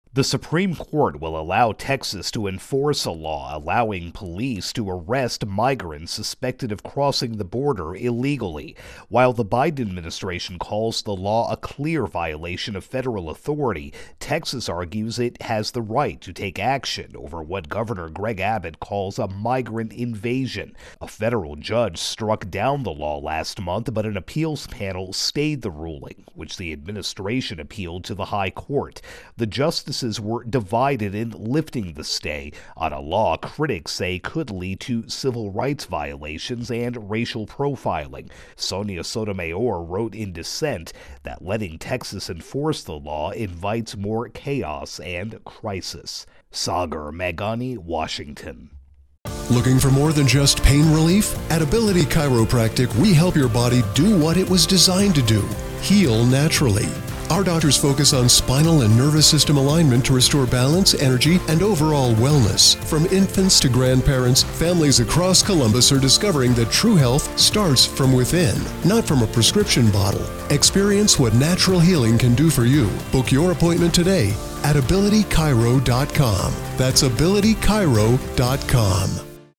reports the Supreme Court has lifted a stay on a law allowing police broad powers to arrest migrants suspected of illegally crossing the border.